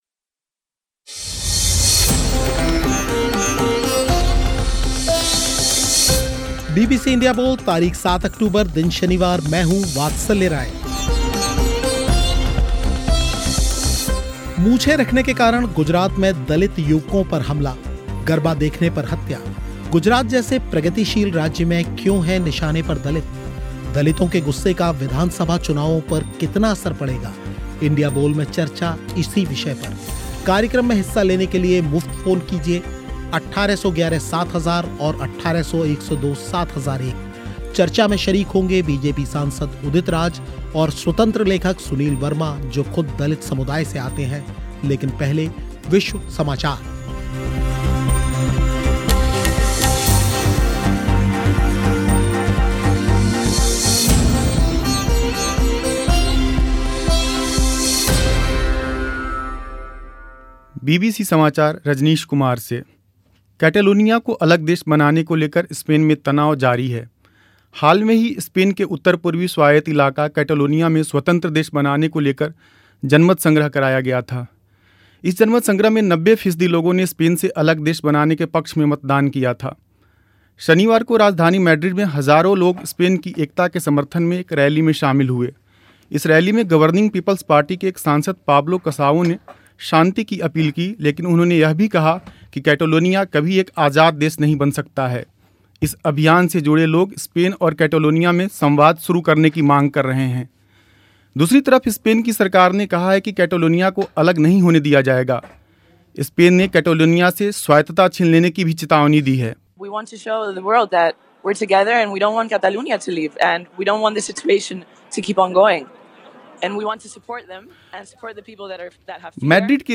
गुजरात जैसे प्रगतिशील राज्य में क्यों हैं निशाने पर दलित दलितों के गुस्से का विधानसभा चुनावों पर कितना असर पड़ेगा इंडिया बोल में इसी मुद्दे पर हुई चर्चा